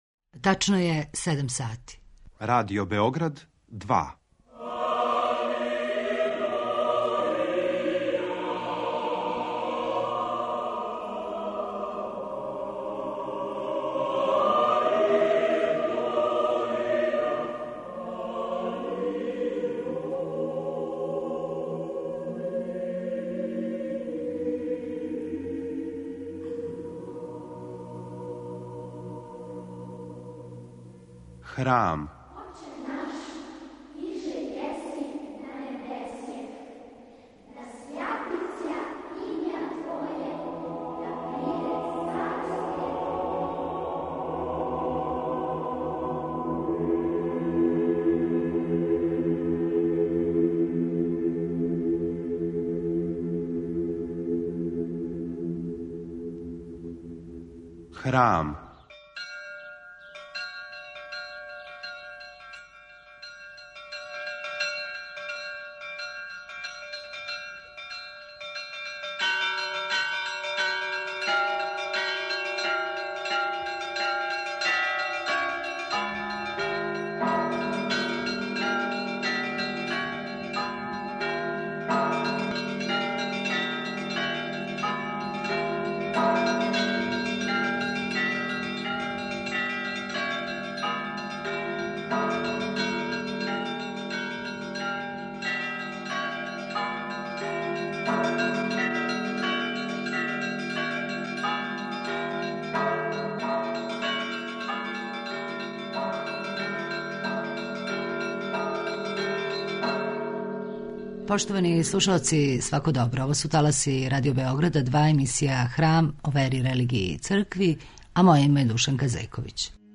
Поводом највећег хришћанског празника на почетку данашњег Храма слушамо део Васкршње посланице патријарха српског господина Иринеја у којој, између осталог, Његова светост указује са чиме се суочава савремени свет, где је у изазовима данашњице место Цркве у друштву, говори о важности великог јубилеја-осам векова аутокефалности Српске православне цркве, подсећа на распето Косово и Метохију, али и на важност опроштаја и сведочења љубави.